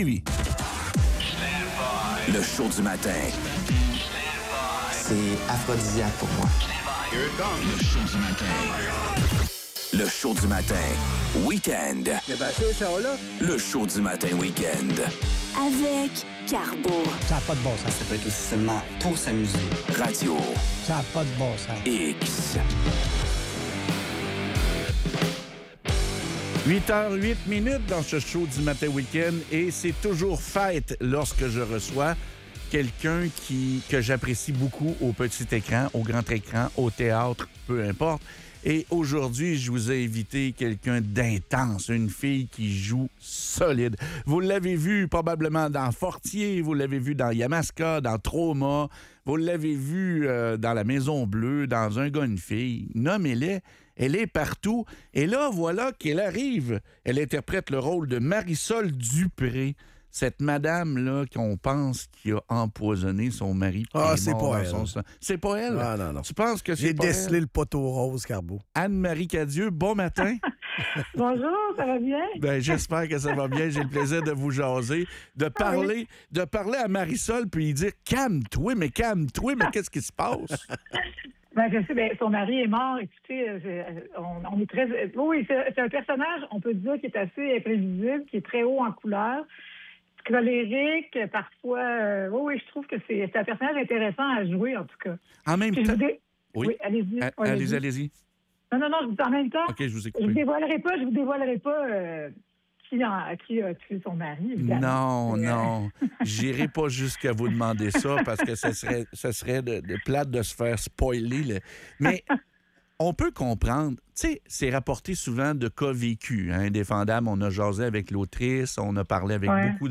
Entrevue avec Anne-Marie Cadieux